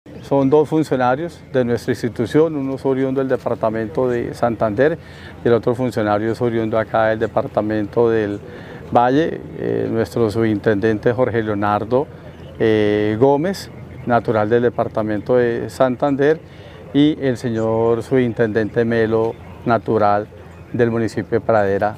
General Henry Bello, comandante Policía Metropolitana de Cali